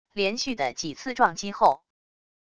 连续的几次撞击后wav音频